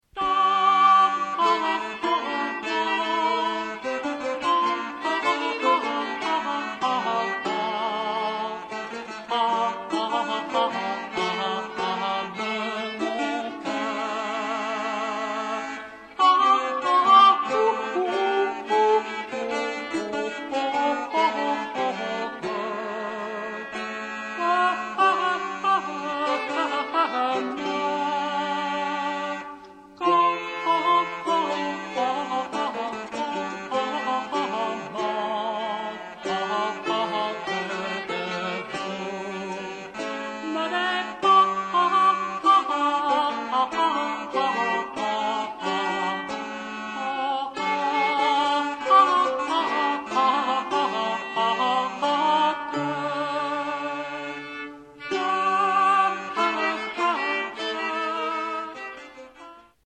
Rondeau
voce e strumenti